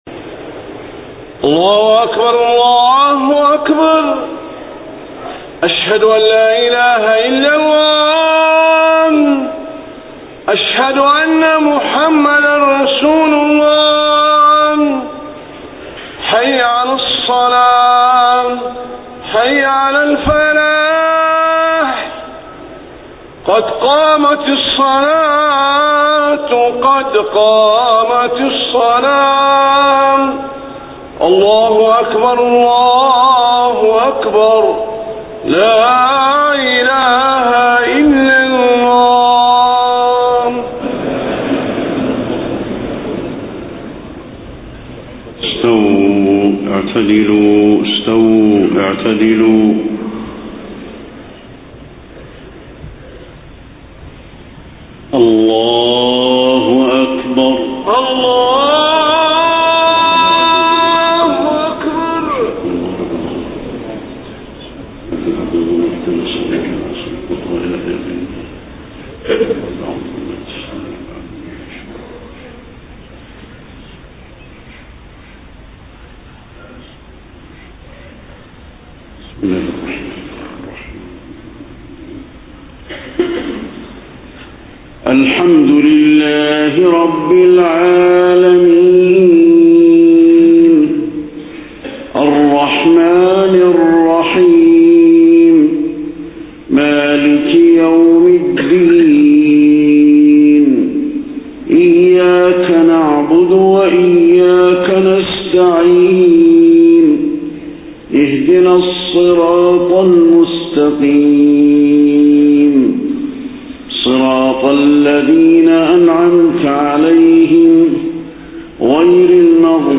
صلاة العشاء 28 صفر 1431هـ من سورة آل عمران > 1431 🕌 > الفروض - تلاوات الحرمين